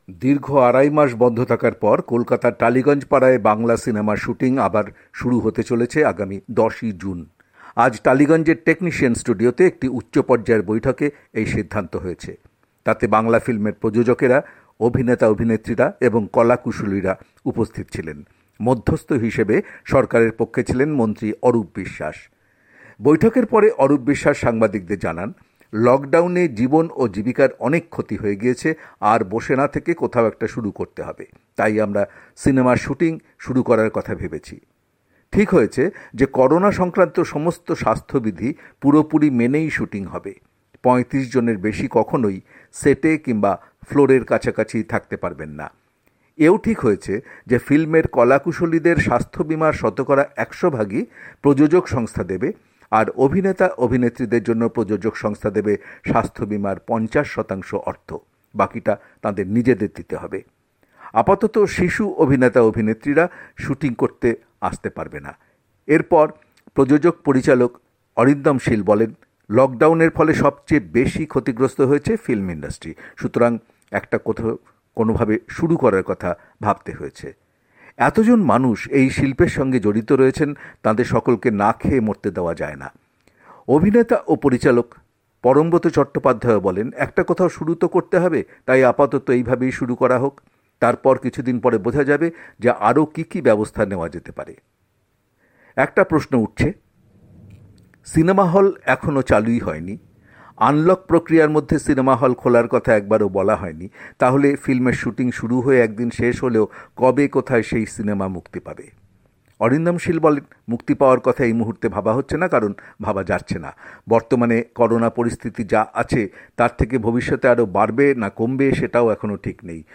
কলকাতা থেকে
রিপোর্ট